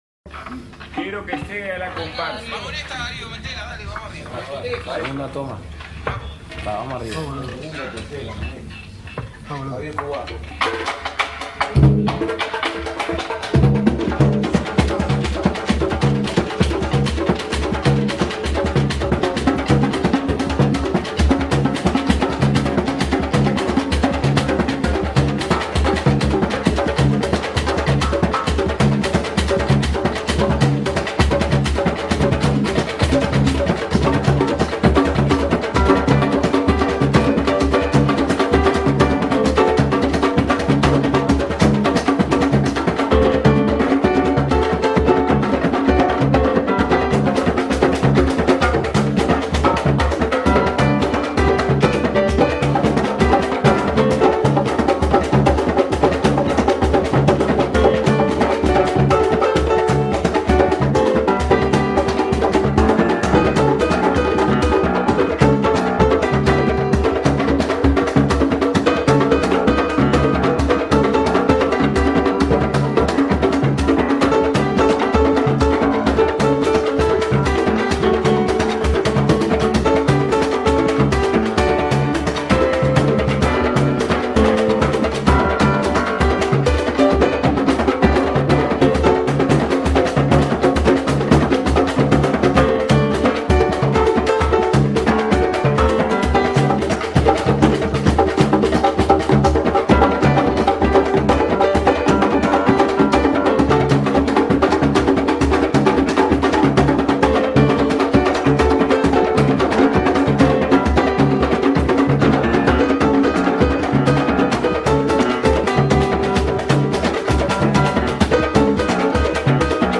Musique candombé : -
candombe.mp3